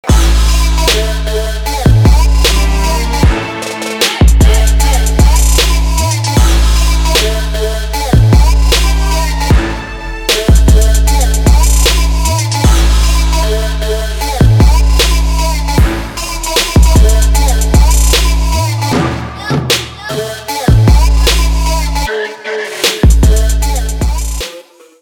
Electronic
EDM
Trap
качающие
клубняк